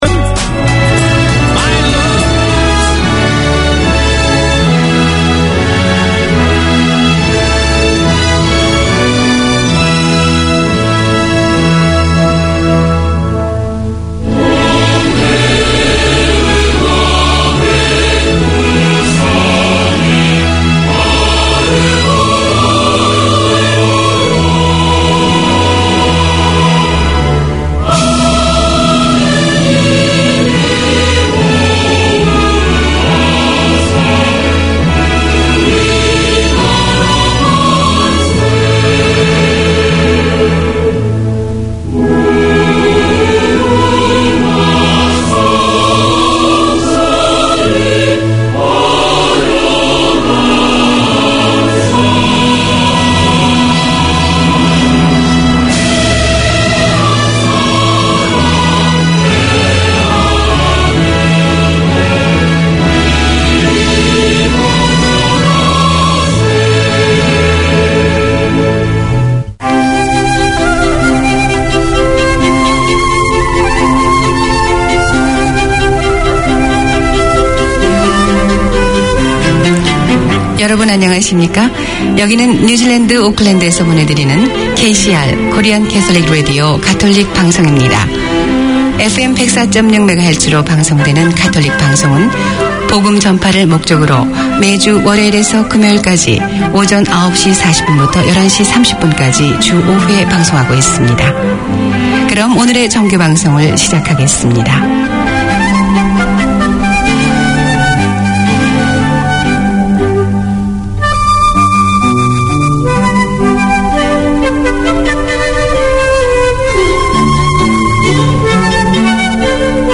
Community magazine